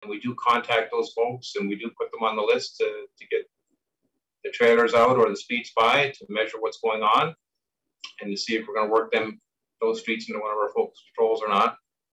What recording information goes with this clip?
Screengrab from meeting of Quinte West Police Services Board June 21/2021.